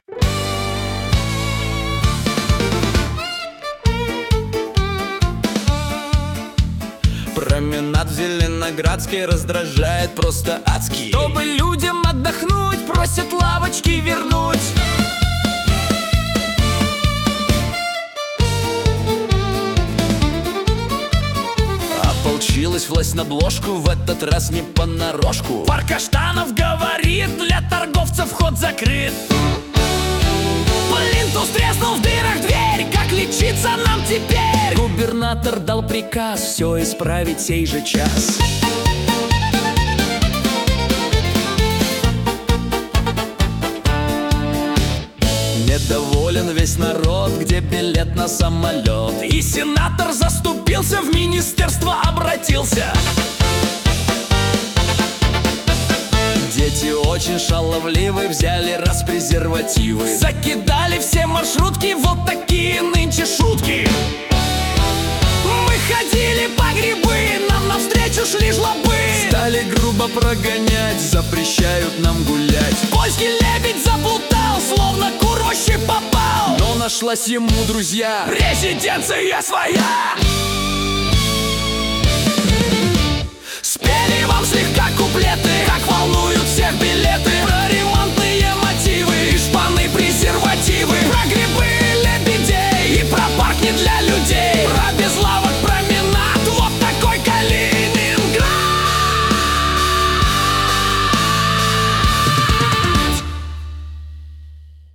Куплеты на злобу дня о главных и важных событиях